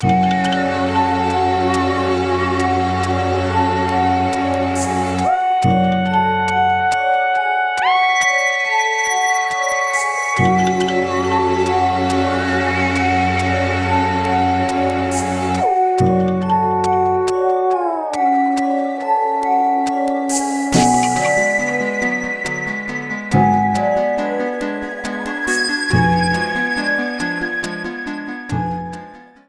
A song